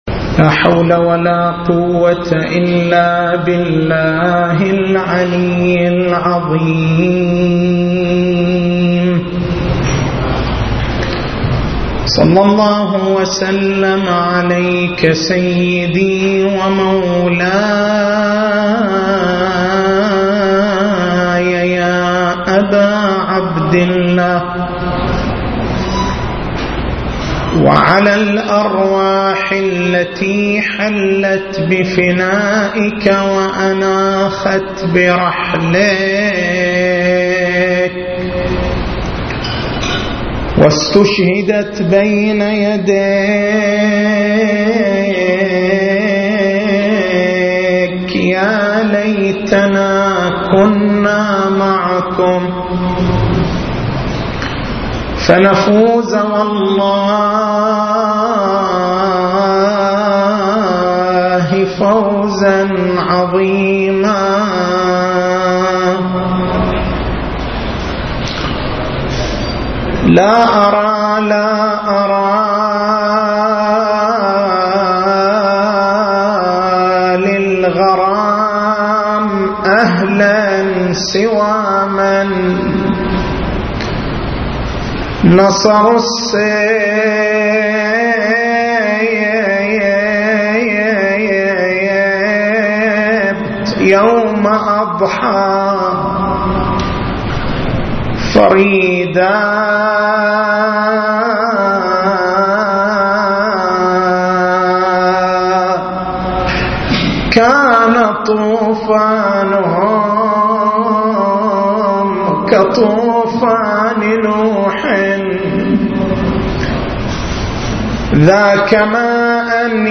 تاريخ المحاضرة: 17/09/1433 محور البحث: ما هي فلسفة تثنية الشهادة لله تعالى بالوحدانية في الأذان؟